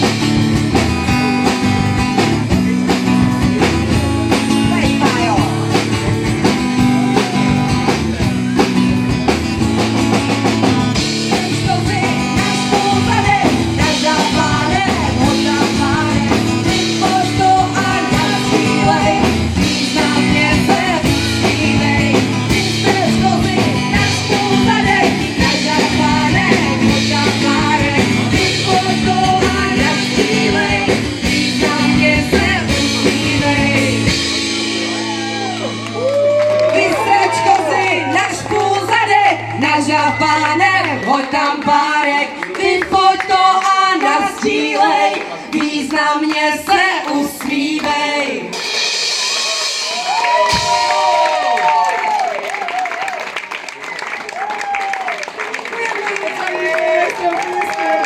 Zijem-online-ukazka-ze-svatby.mp3